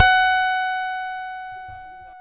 Index of /90_sSampleCDs/Roland - Rhythm Section/BS _Rock Bass/BS _Chapmn Stick